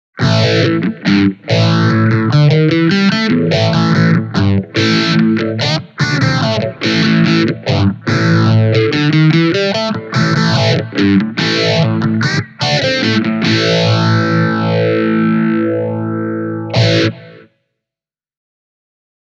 I played these clips on my Kasuga (a Japanese ES-335 copy from the Seventies), recording the sound with two dynamic Shure microphones.
Crunch: